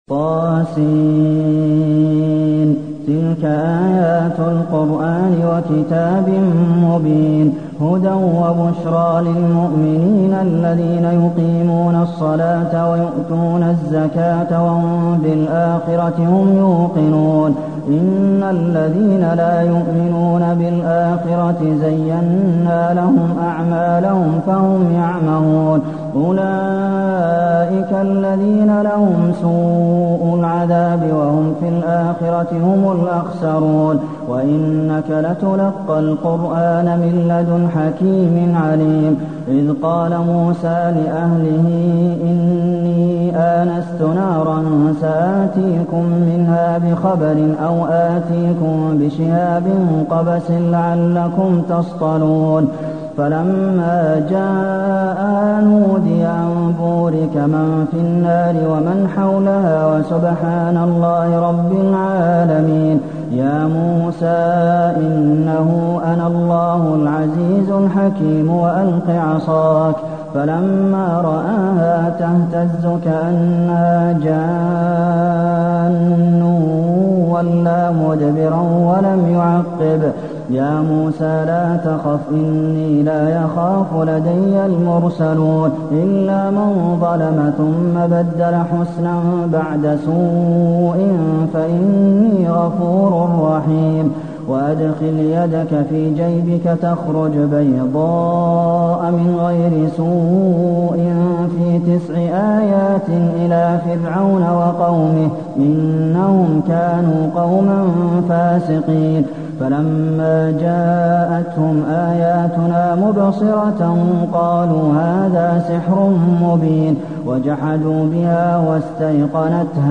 المكان: المسجد النبوي النمل